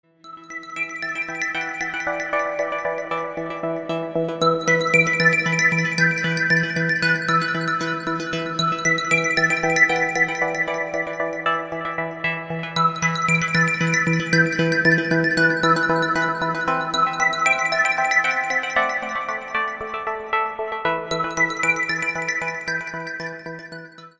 • Качество: 128, Stereo
атмосферные
спокойные
красивая мелодия
Electronica
nu disco
Indie Dance